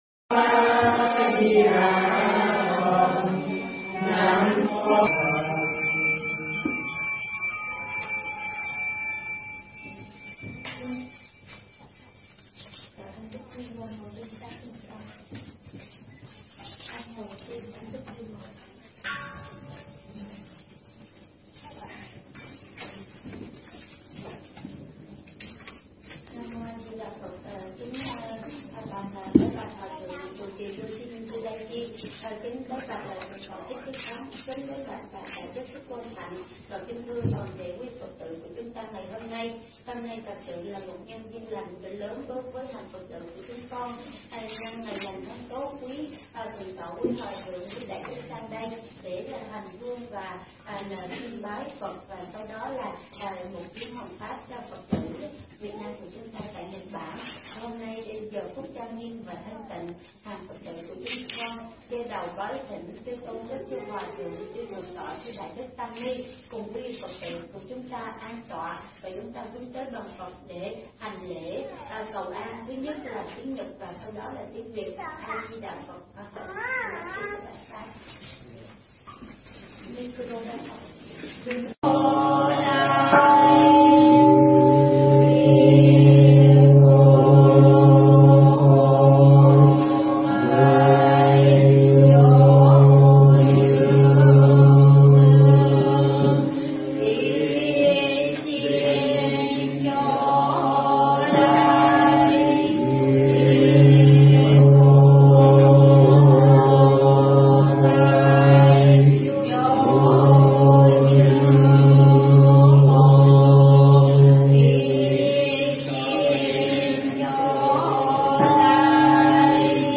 Nghe Mp3 thuyết pháp Ứng Dụng Phật Pháp Trong Cuộc Sống